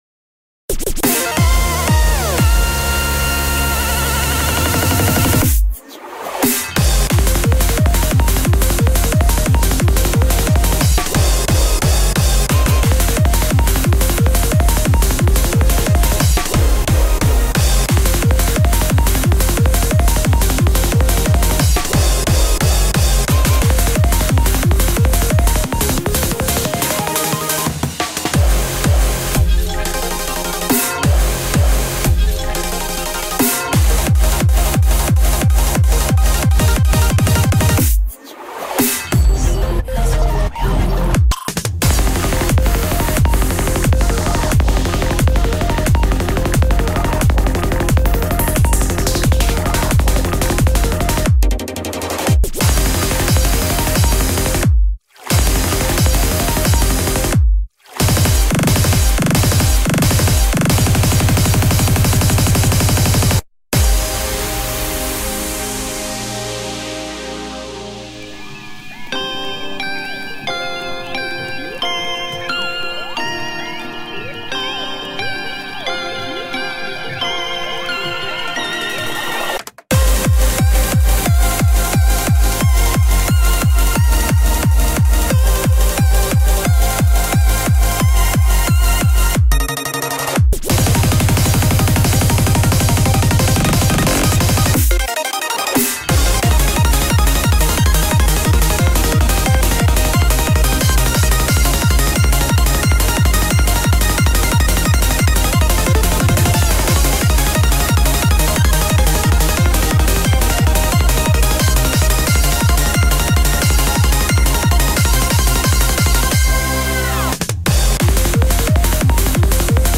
BPM89-178
Comments[OUTER SPACE CHIPTUNE]